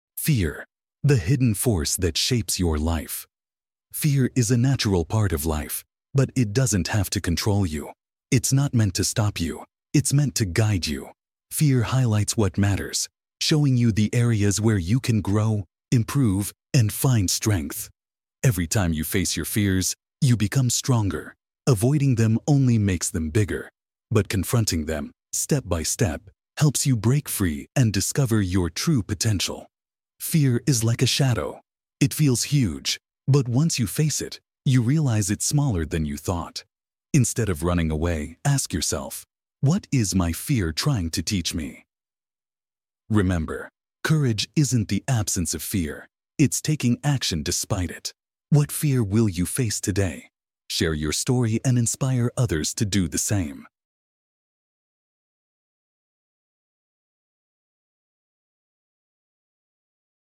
daily english practice by reading and listening to short stories.